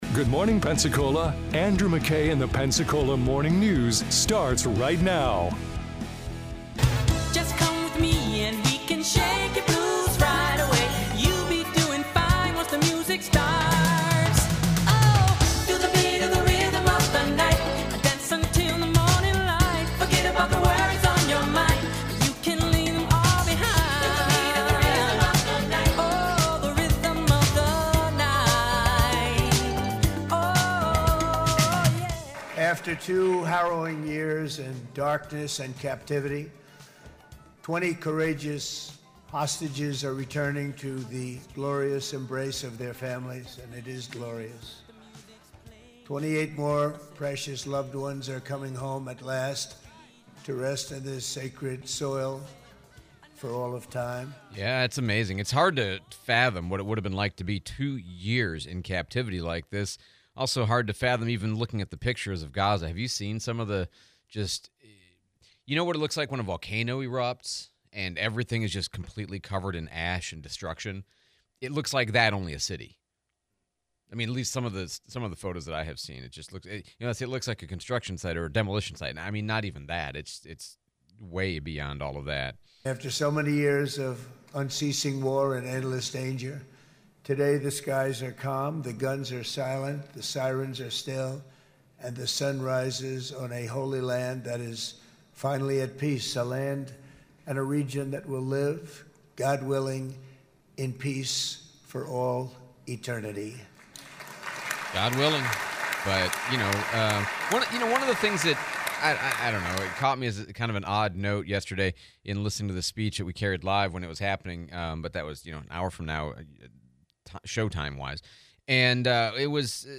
Trump Speech, Replay of US Congressman Jimmy Patronis